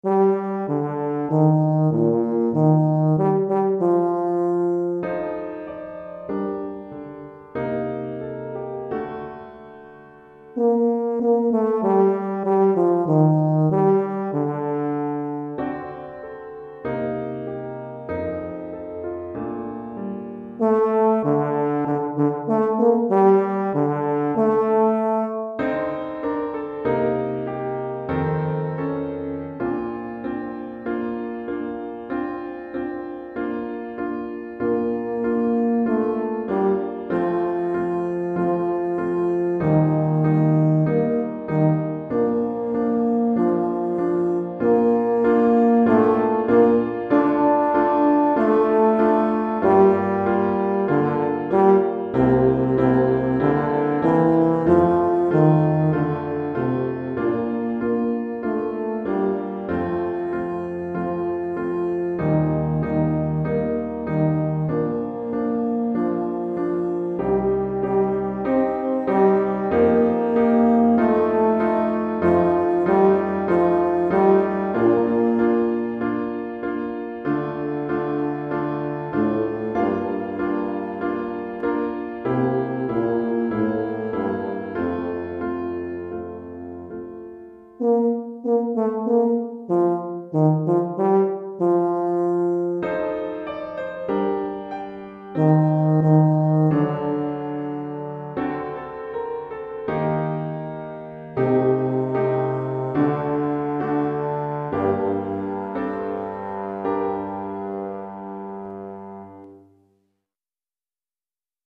Euphonium ou Saxhorn ou Tuba Clé de Fa Do ou et Eu